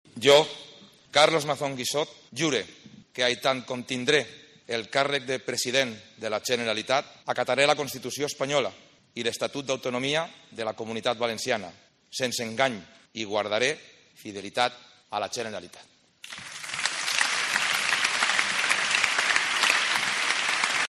Carlos Mazón jura el cargo de president de la Generalitat